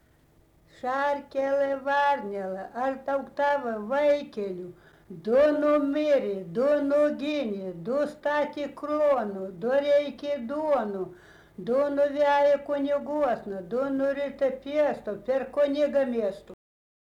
daina, vaikų
Bražuolė
vokalinis